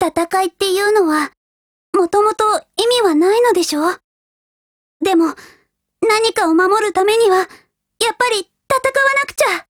贡献 ） 协议：Copyright，其他分类： 分类:少女前线:MP5 、 分类:语音 您不可以覆盖此文件。
MP5Mod_DIALOGUE3_JP.wav